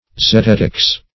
Search Result for " zetetics" : The Collaborative International Dictionary of English v.0.48: Zetetics \Ze*tet"ics\, n. [See Zetetic , a.]